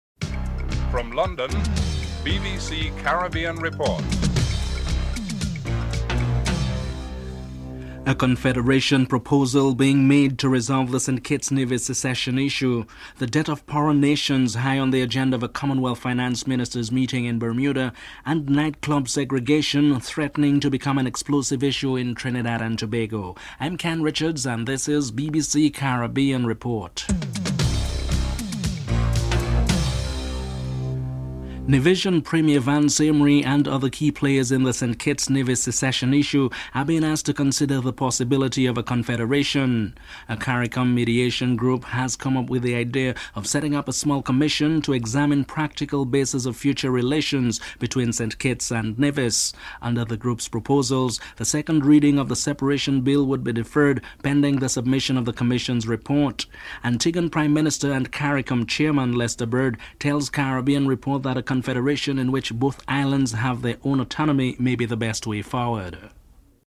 2. A confederation proposal being made to resolve the St. Kitts and Nevis secession issue. Prime Minister and Caricom Chairman Lester Bird is interviewed (00:31-04:23)
4. Montserrat's Chief Minister Reuben Meade does not think it likely that an emergency parliamentary session will be called to debate a motion of no confidence. Chief Minister Reuben Meade is interviewed (05:06-08:03)
8. Prime Minister Vaughn Lewis says the St. Lucian government will continue to play a meaningful role in the island's banana industry. Prime Minister Vaughn Lewis is interviewed (11:19-12:34)